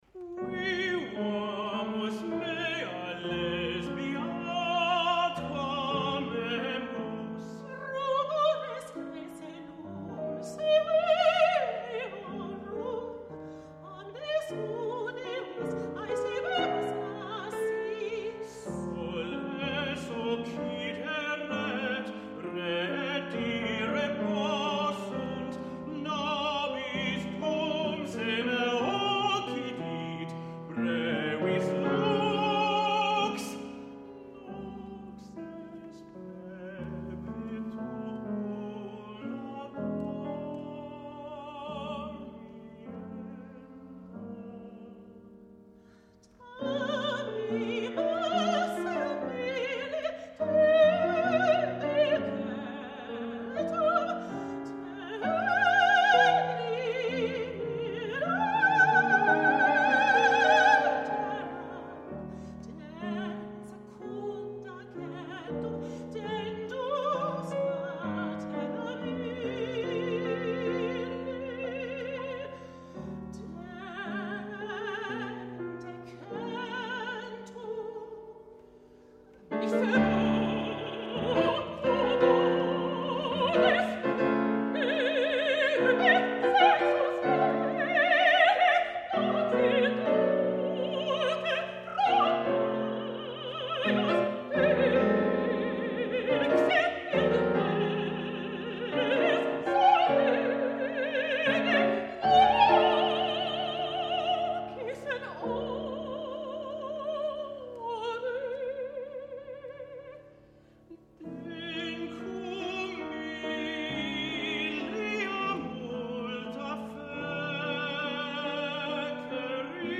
Selections from this work-in-progress were performed with piano accompaniment at Willamette University, Salem, Oregon, on March 13, 2013.